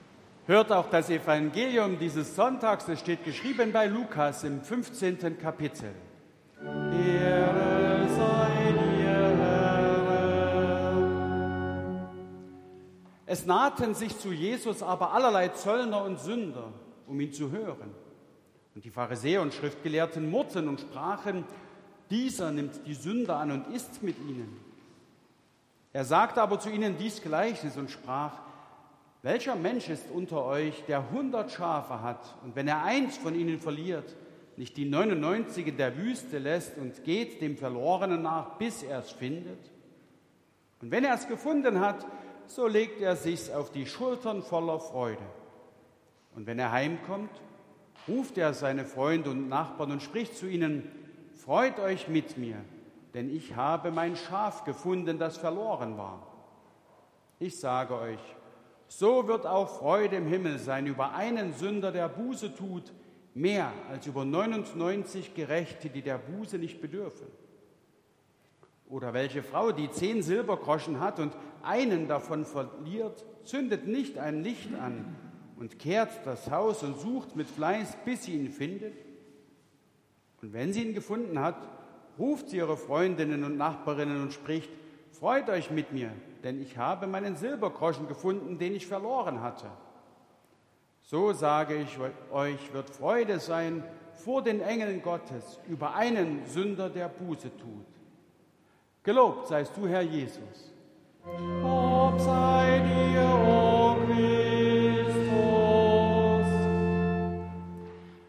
7. Lesung aus Lukas 15,1-10 Ev.-Luth.
Audiomitschnitt unseres Gottesdienstes am 3.Sonntag nach Trinitatis 2024